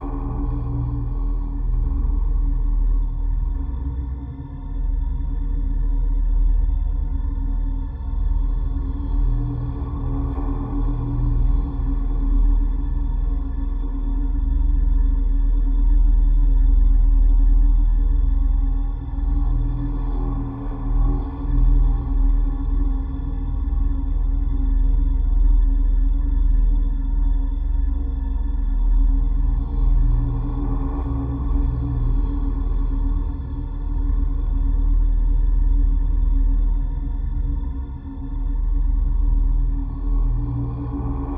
Nightmare ambiences Demo
Nightmare_ambience_11_3.wav